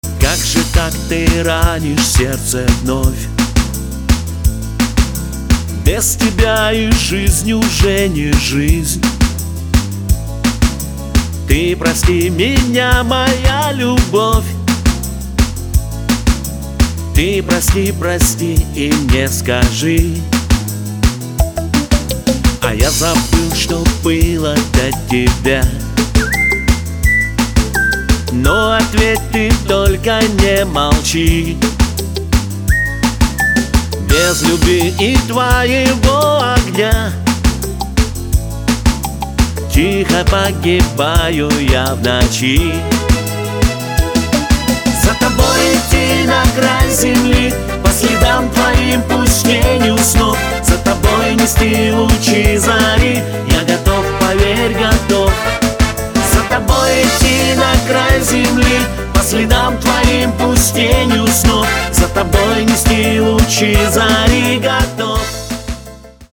• Качество: 224, Stereo
свист
мужской вокал
русский шансон